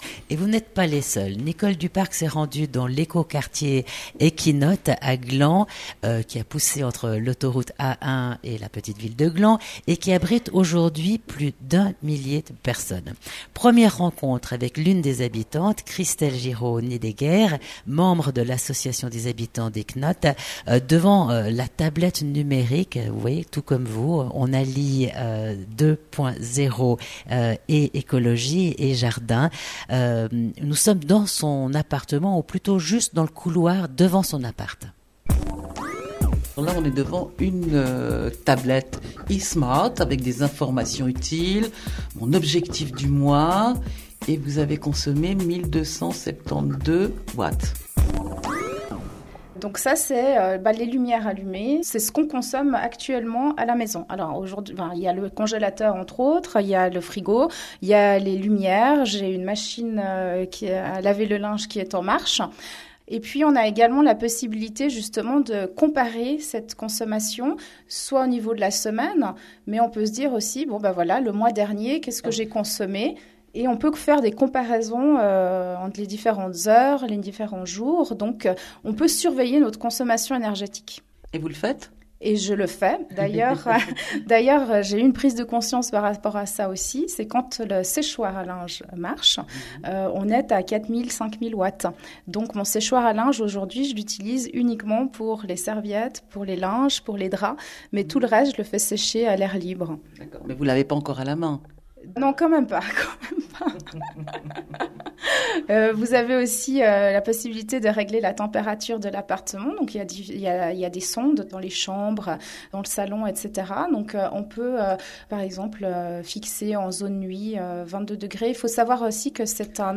A l’occasion de l’ouverture du sommet COP21, l’émission Babylone, diffusée sur RTS/Espace 2, a consacré un reportage à Eikenøtt.